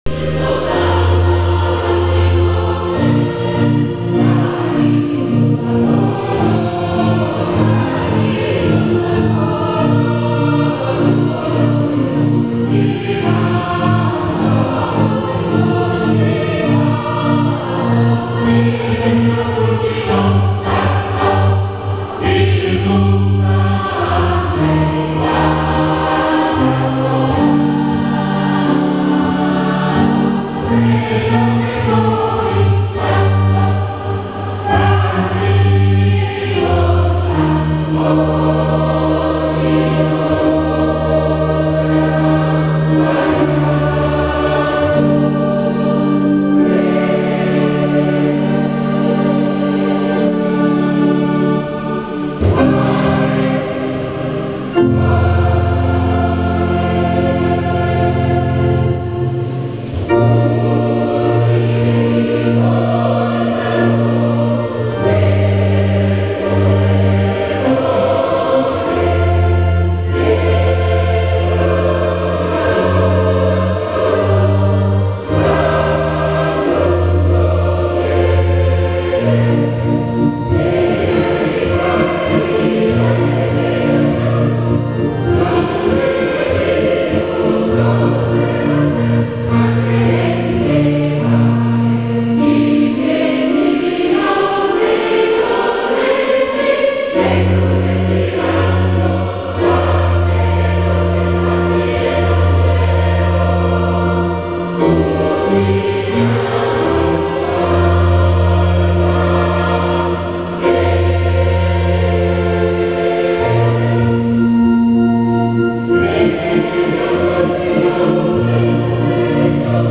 Solenni festeggiamenti in onore di San Casto
La santa messa � stata accompagnata dalla "Corale San Casto" che ha ese�guito nella circostanza l'in�no polifonico che la cittadina di Calvi da anni dedica a San Casto, inno com�posto dal compianto Mae�stro Sac.